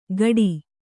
♪ gaḍi